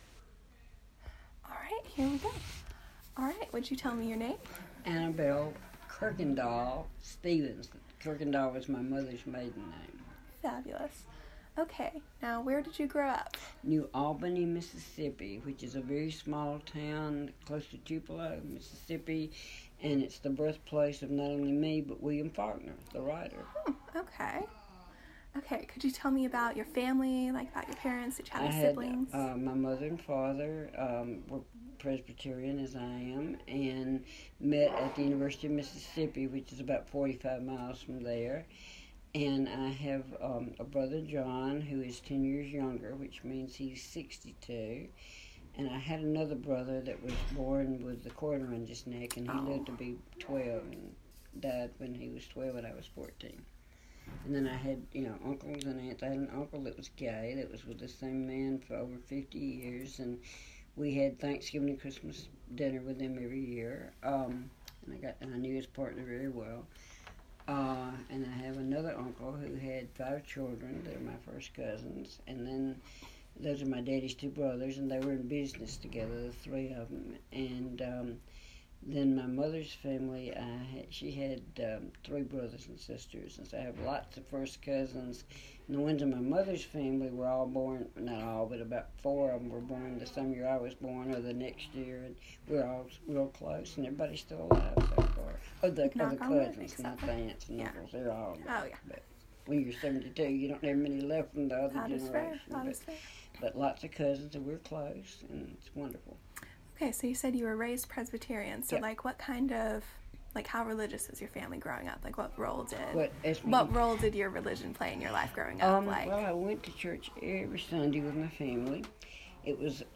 Queer Southern History - Oral Histories